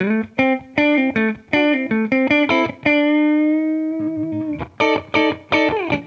Index of /musicradar/sampled-funk-soul-samples/79bpm/Guitar
SSF_TeleGuitarProc1_79D.wav